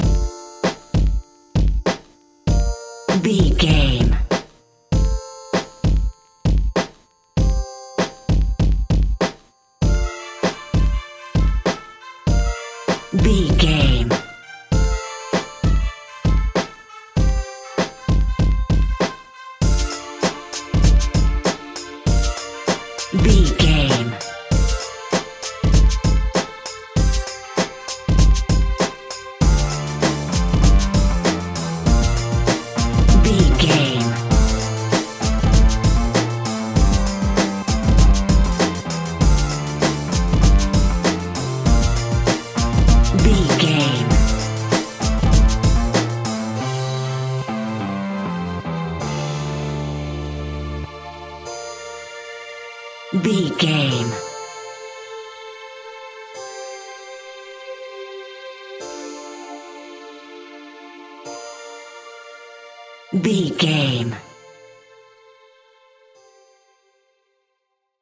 Aeolian/Minor
D
hip hop instrumentals
downtempo
synth lead
synth bass
synth drums
turntables